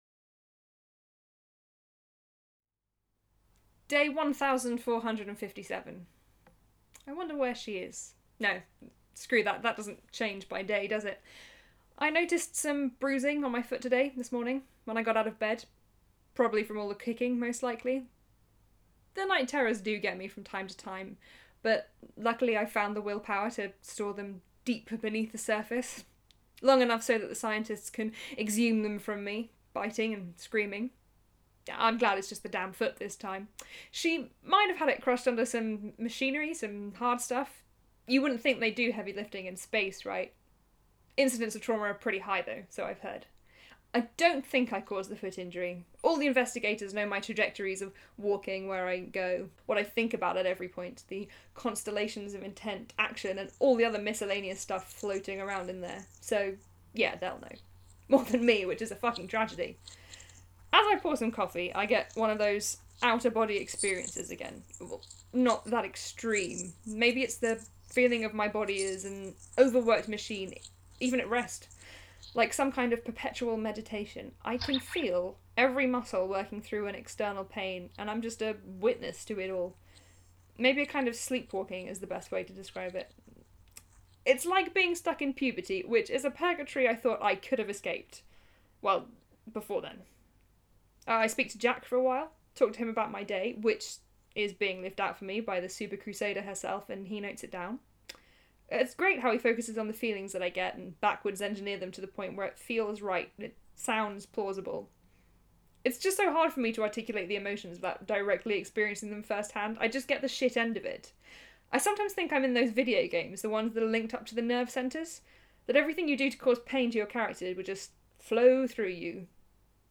Actor and Electronics
The monologue depicts the experiences of a character on Earth linked with her clone in space. The soundscape is used to depict moments of shared experience between clones.